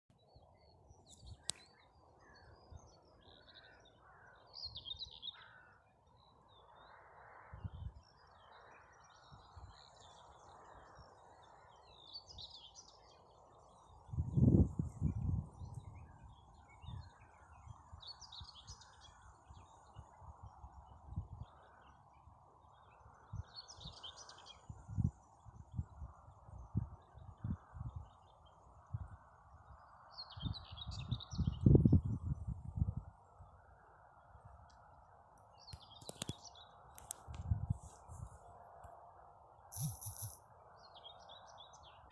Птицы -> Славковые ->
серая славка, Curruca communis
СтатусПоёт